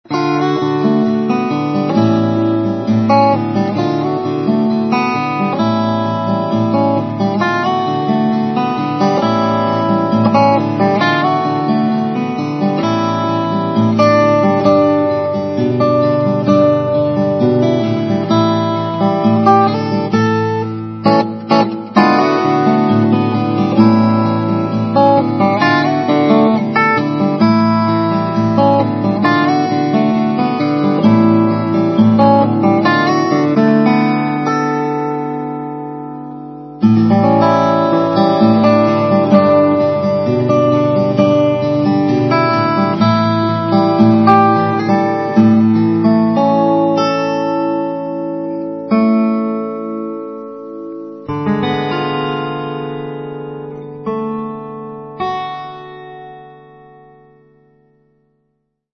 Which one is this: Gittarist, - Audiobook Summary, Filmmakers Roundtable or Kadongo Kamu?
Gittarist